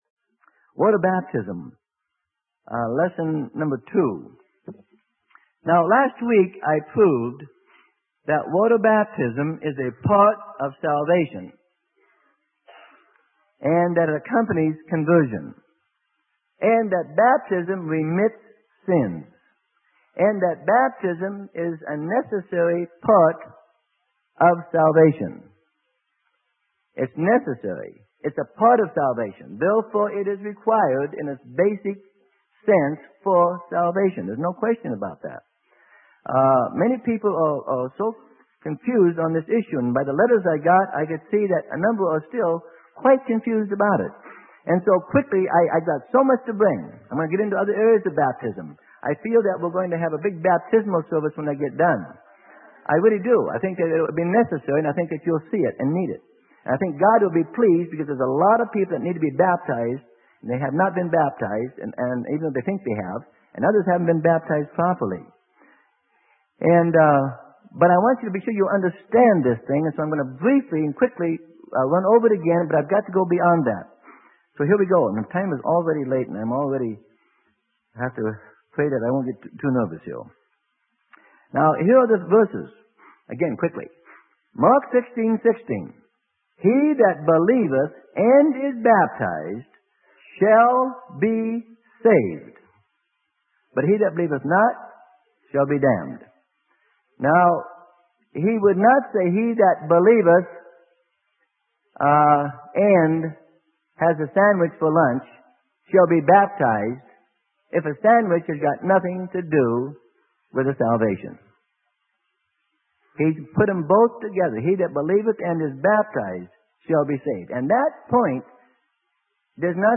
Sermon: The Necessity of Water Baptism - Part 2 - Freely Given Online Library